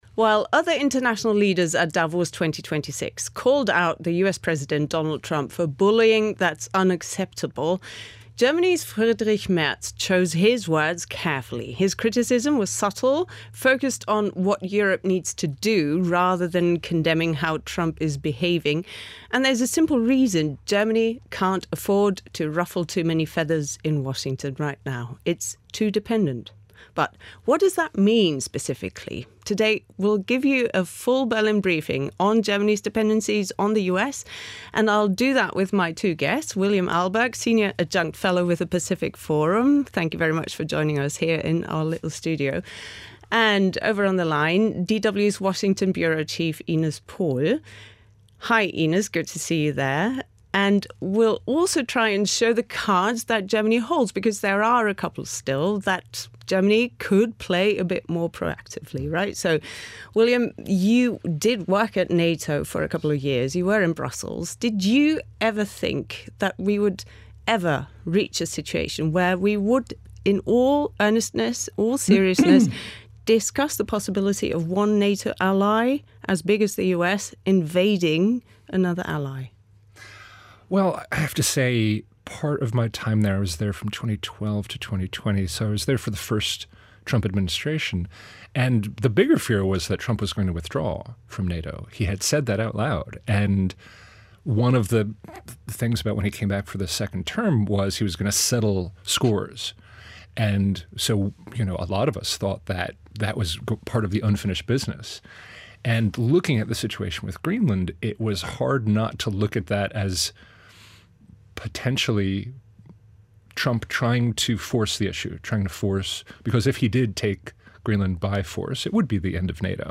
talks with her guests about Germany’s deep dependencies on the US -from NATO cooperation to nuclear deterrence.